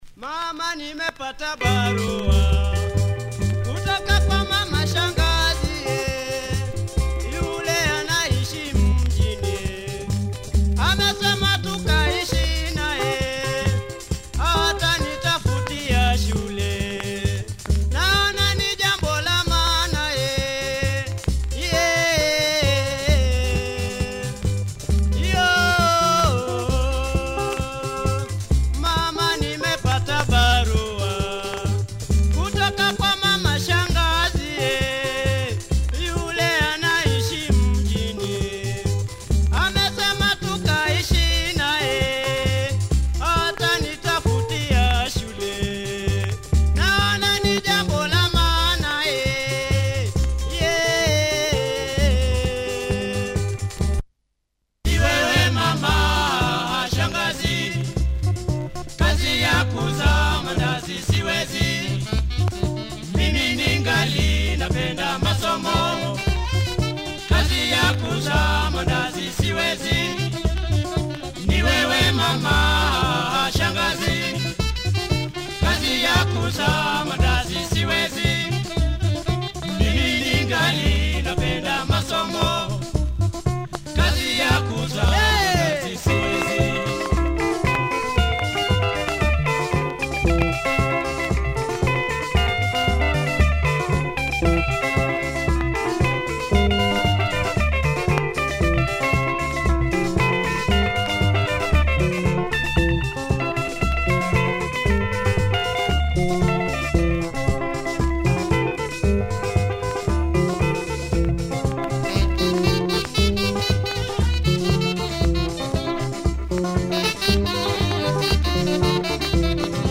congolese mode here